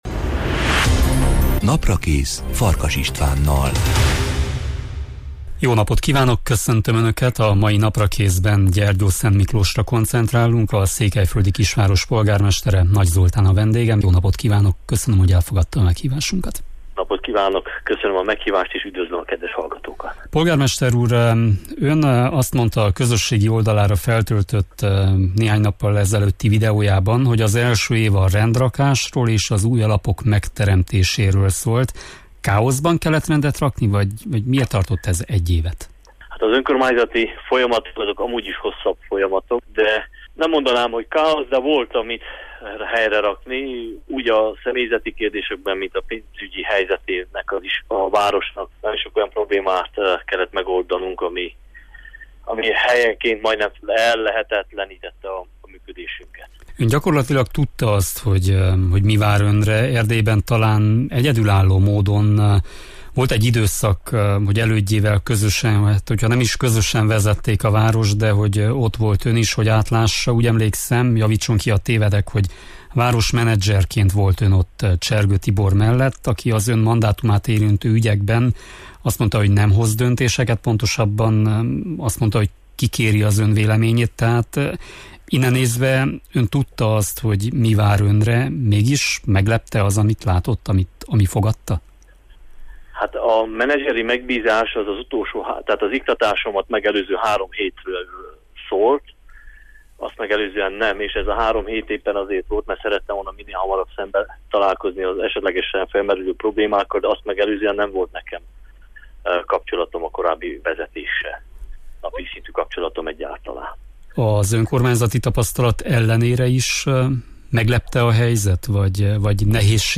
A mai Naprakészben Gyergyószentmiklós polgármesterével, Nagy Zoltánnal beszélgetünk arról, hogyan telt az első év a város élén, és mit jelentett számára az „alapozás éve”. Szó lesz arról, hogy mekkora mértékbensikerült rendet tenni a város ügyeiben, milyen eredmények látszanak a mindennapokban, és mire számíthatnak a gyergyóiak az „építkezés” időszakában.